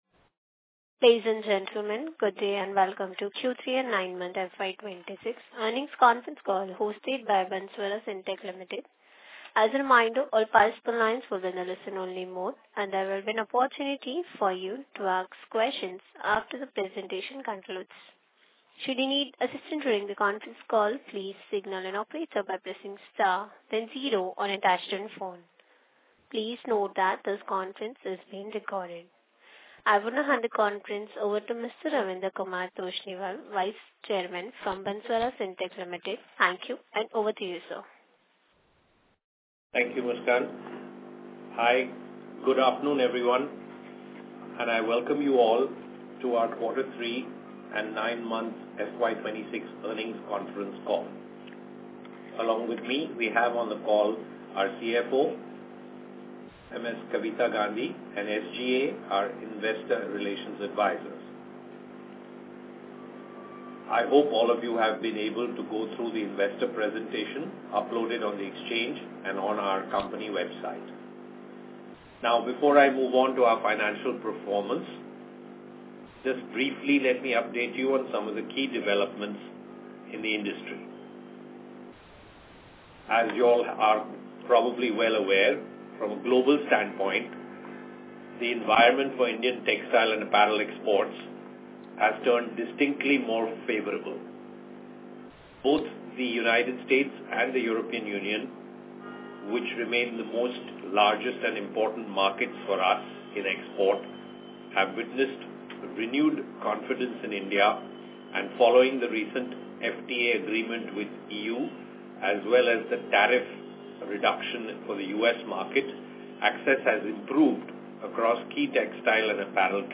Transcript of Earning Conference Call - BANSWARA SYNTEX LIMITED